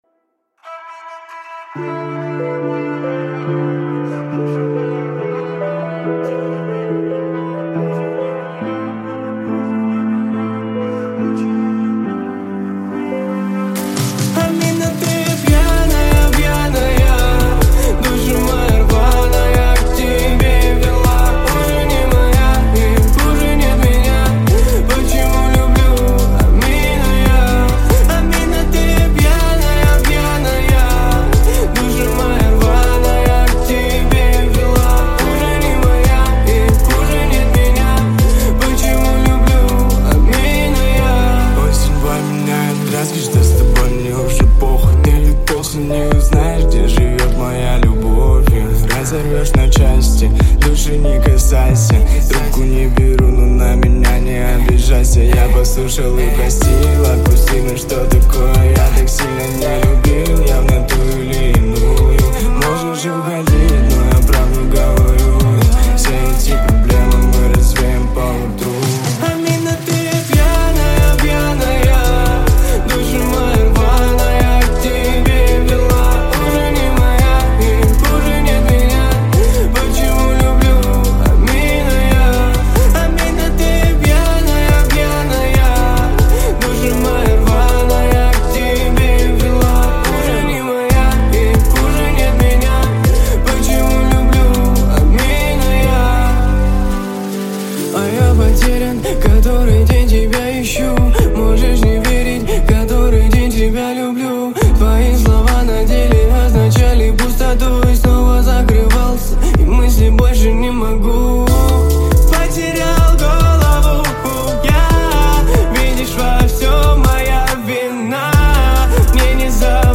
это завораживающий трек в жанре альтернативного R&B